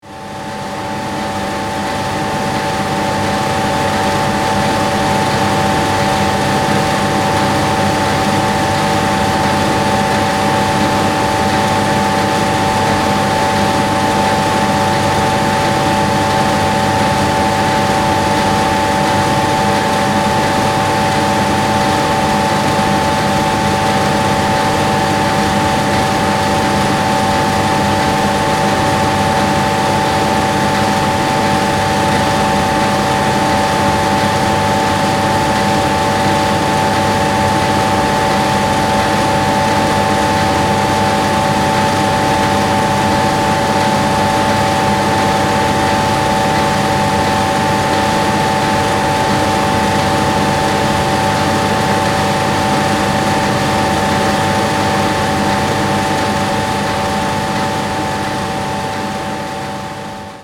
Hydro power plant
• Soundscape